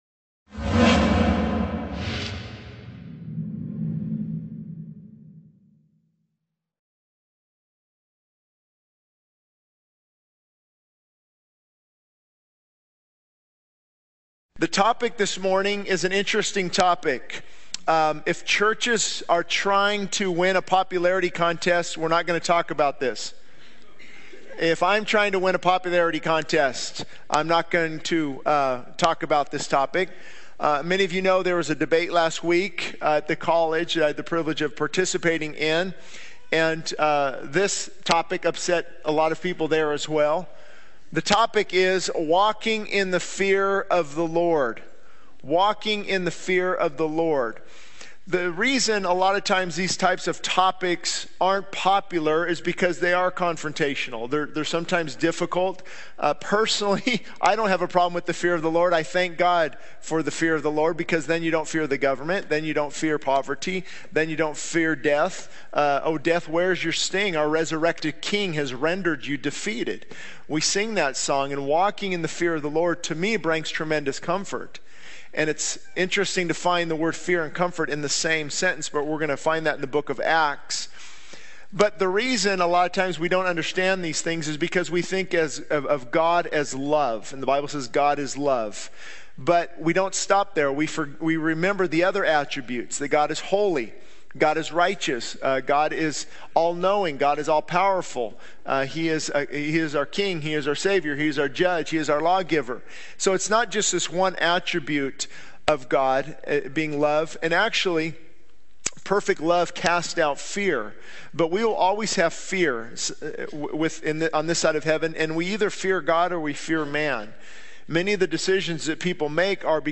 This sermon emphasizes the importance of walking in the fear of the Lord, highlighting the confrontational nature of this topic and the need to understand God's attributes beyond just love. It discusses the impact of fearing God on overcoming worldly fears, finding comfort in reverence, and experiencing God's peace and multiplication in life.